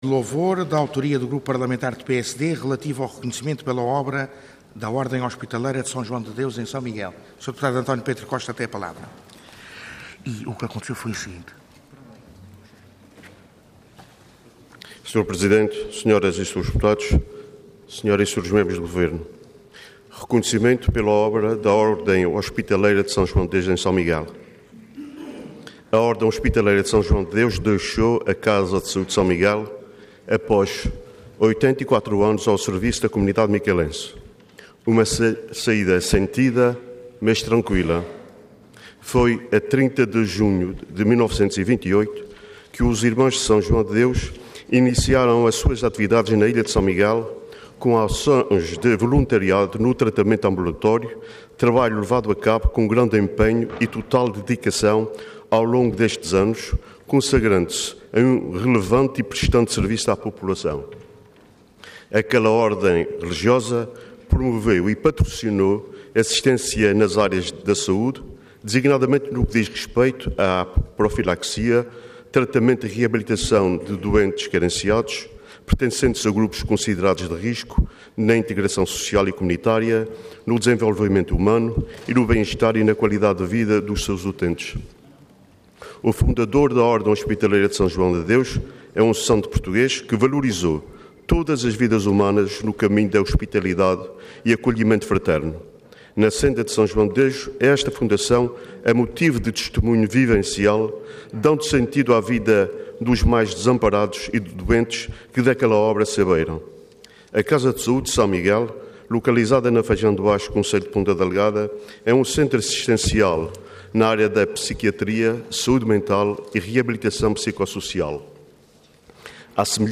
Website da Assembleia Legislativa da Região Autónoma dos Açores